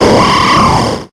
infinitefusion-e18/Audio/SE/Cries/ARMALDO.ogg at 290b6f81d1f6594caaa3c48ce44b519b0dfb49f9